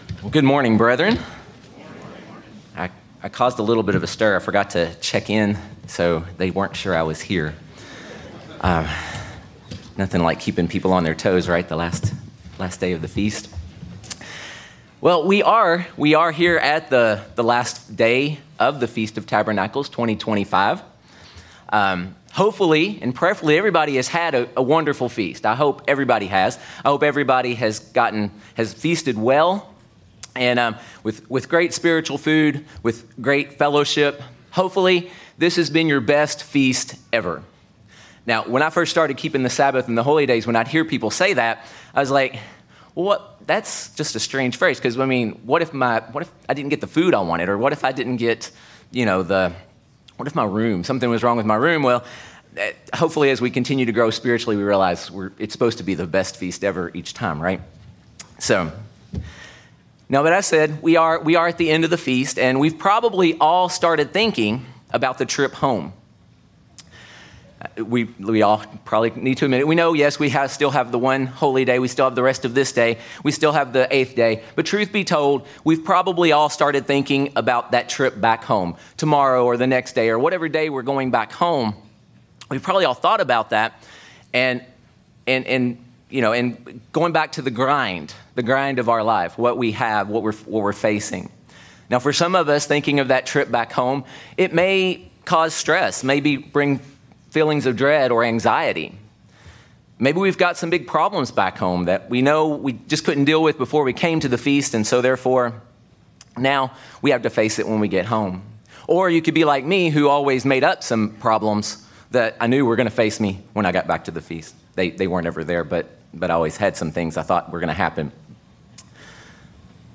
Given in Branson, Missouri